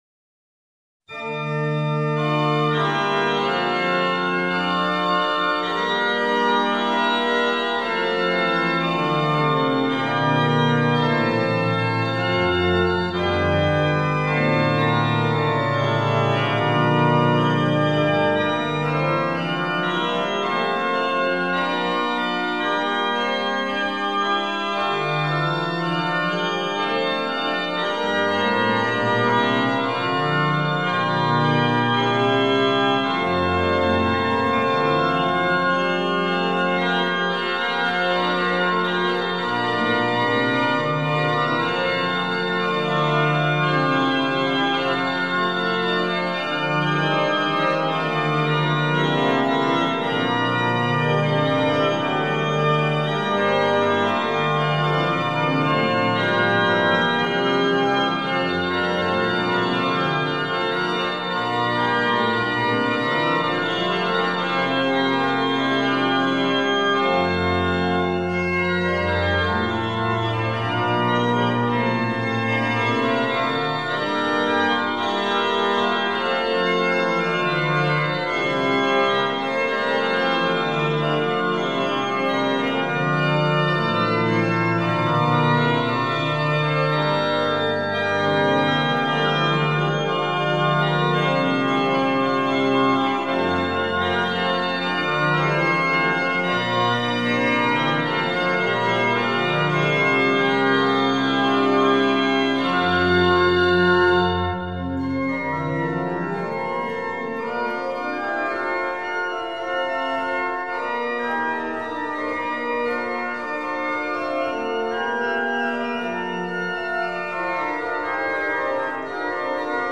Antonio de Cabezón (1500–1566), música barroca española, órgano de Valère.